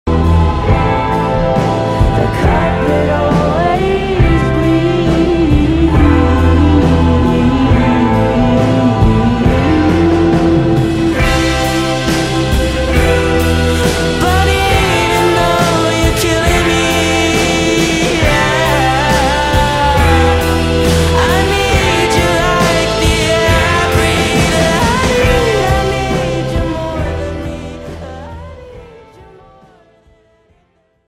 bruh sound effects free download